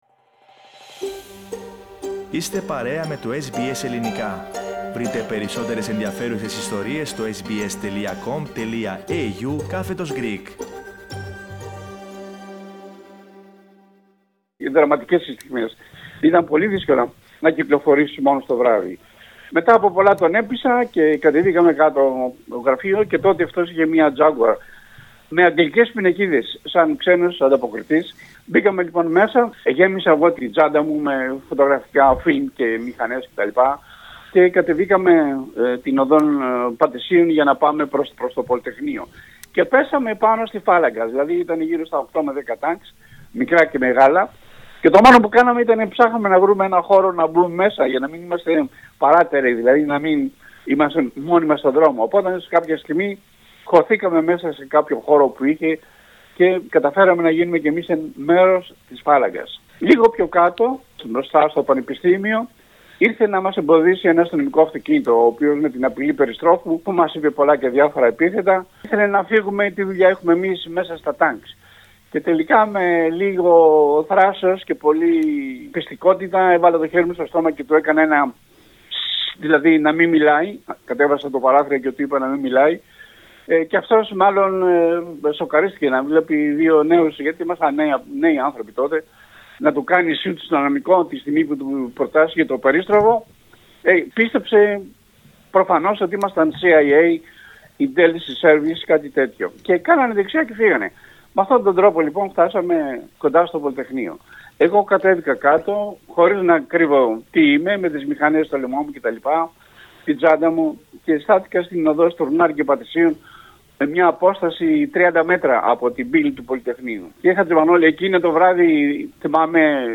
μιλώντας στο Ελληνικό Πρόγραμμα της Ραδιοφωνίας SBS